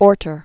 (ôrtər), Alfred Adolf Known as "Al." 1936-2007.